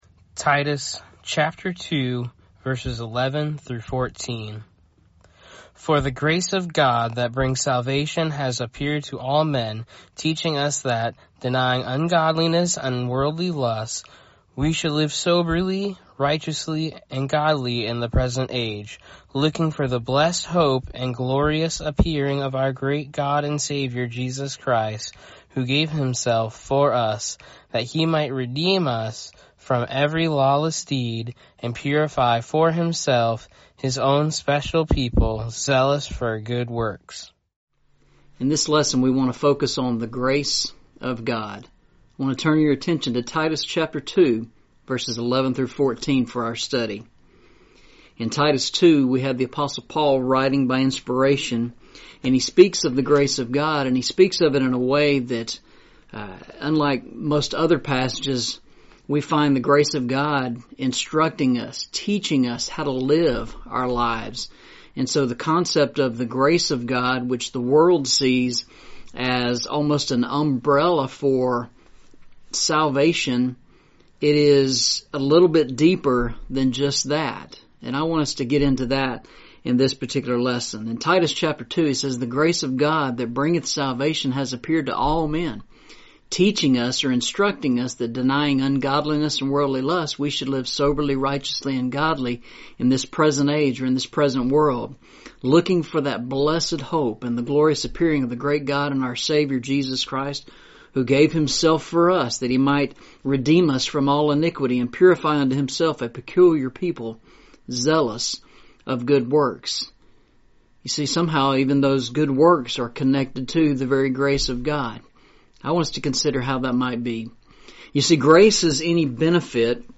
Series: Eastside Sermons
Eastside Sermons Service Type: Sunday Morning Preacher